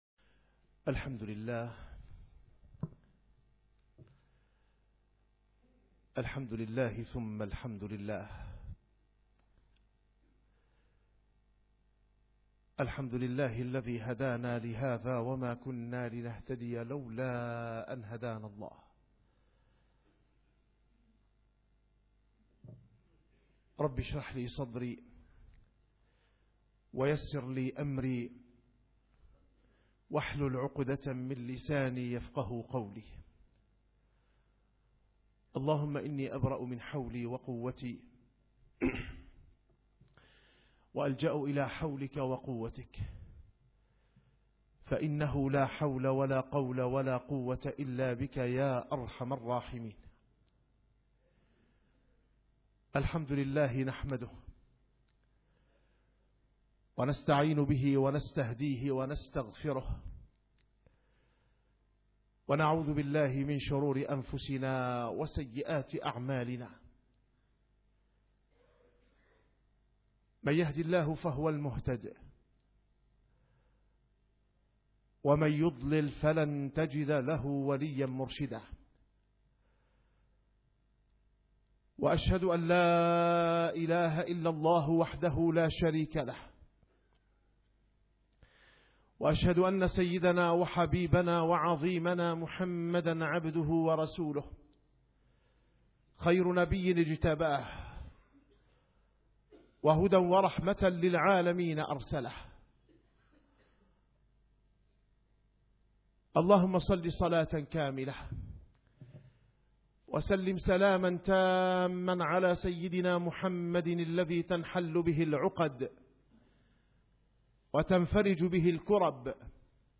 نسيم الشام › - الخطب - مفهوم البلاء عند النبي صلى الله عليه وسلم في مطلع خروجه مهاجرا ً إلى الله